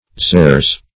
xeres - definition of xeres - synonyms, pronunciation, spelling from Free Dictionary Search Result for " xeres" : The Collaborative International Dictionary of English v.0.48: Xeres \Xer"es\, n. Sherry.